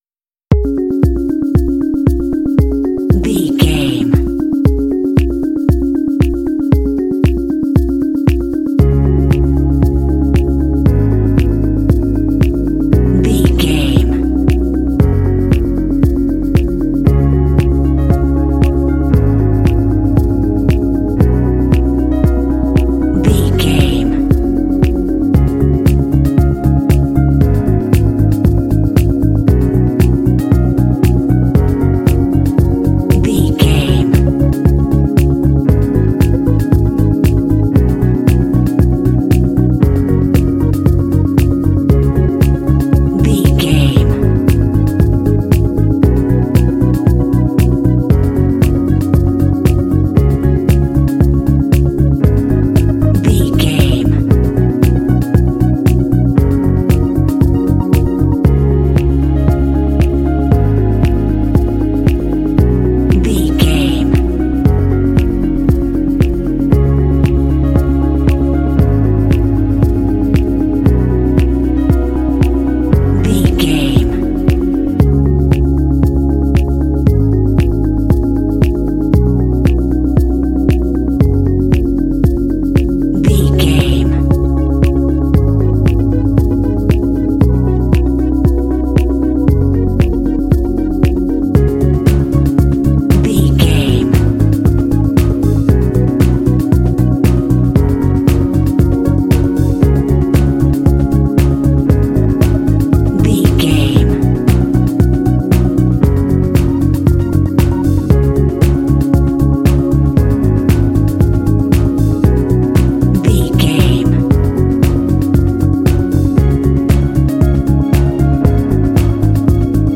Uplifting
Ionian/Major
D♭
sentimental
joyful
hopeful
bright
reflective
bass guitar
synthesiser
piano
drums
strings
contemporary underscore